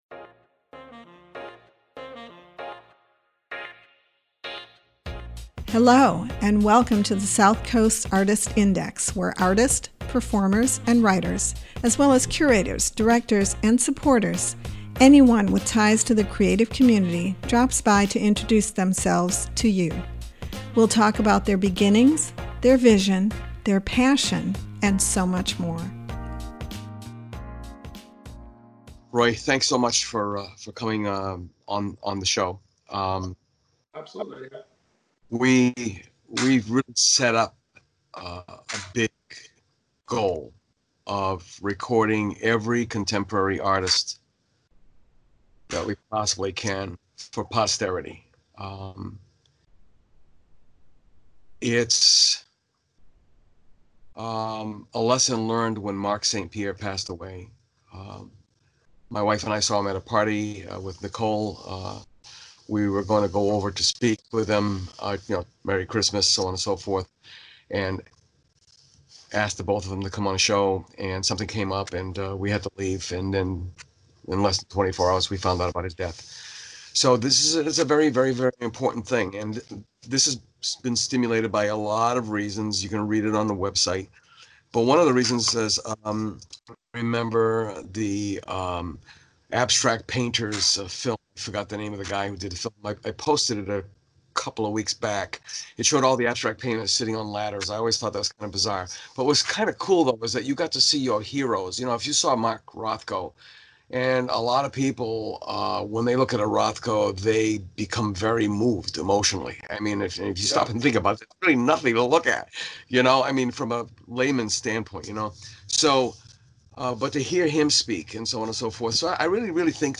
This interview is archived at the New Bedford Whaling Museum as part of the Common Ground oral history project.